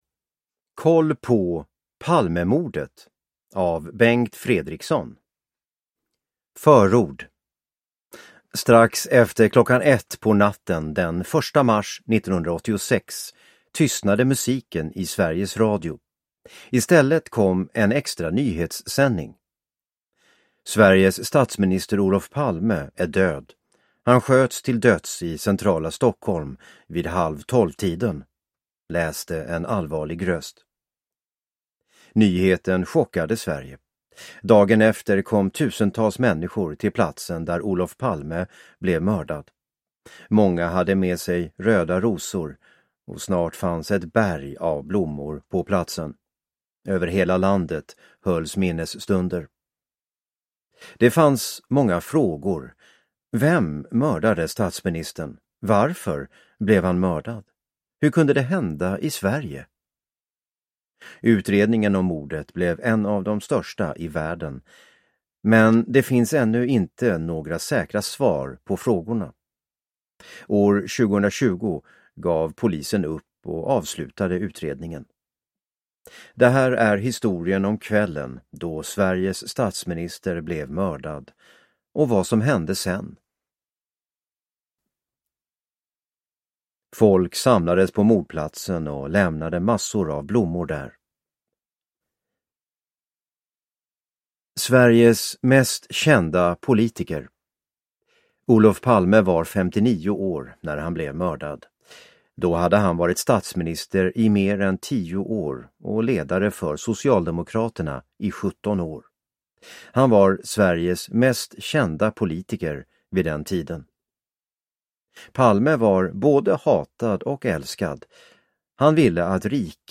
Koll på Palmemordet (ljudbok